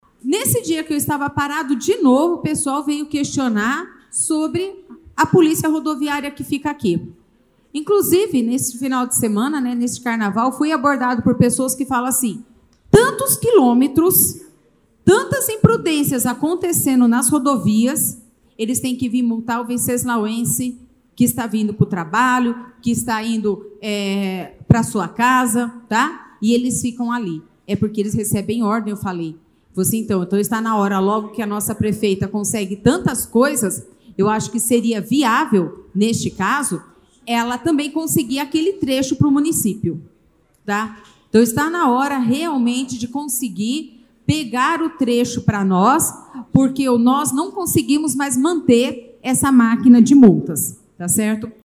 Na última sessão ordinária, realizada na segunda-feira passada, a vereadora Alessandra Pereira de Presidente Venceslau novamente expressou sua frustração em relação à falta de atendimento aos seus requerimentos pelo executivo municipal.
Ouça a fala da vereadora sobre a Polícia Rodoviária: